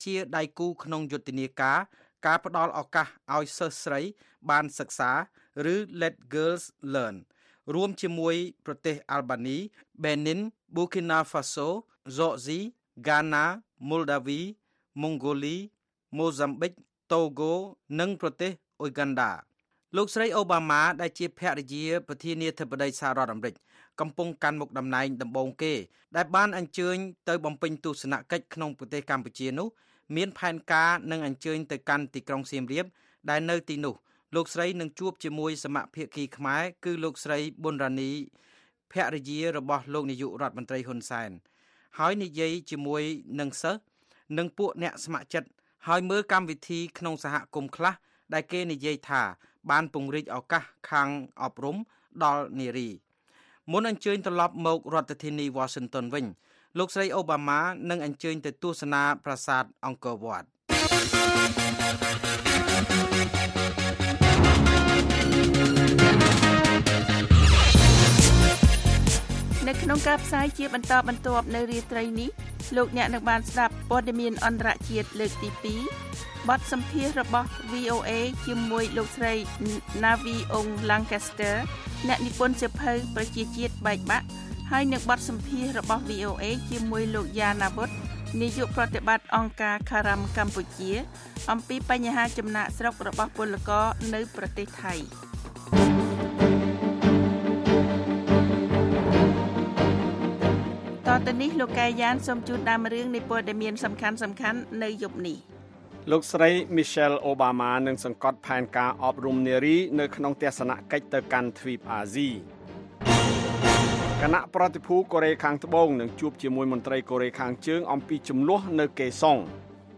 هه‌واڵه‌کان، ڕاپـۆرت، وتووێژ،